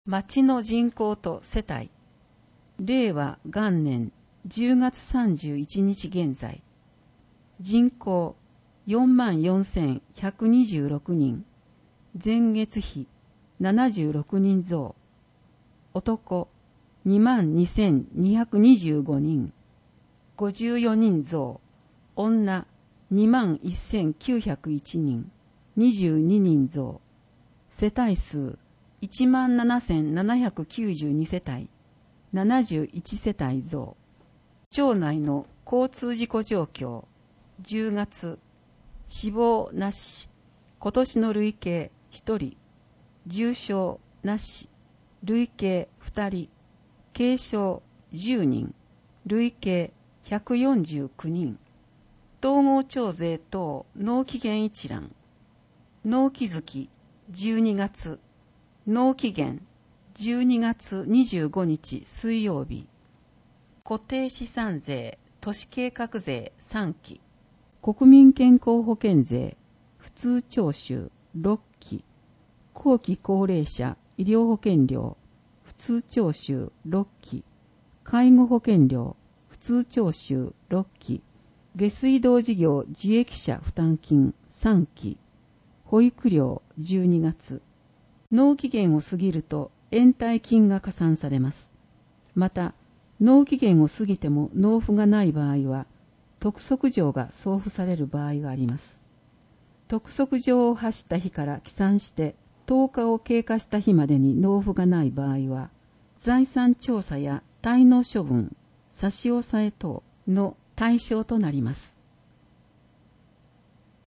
広報とうごう音訳版（2019年12月号）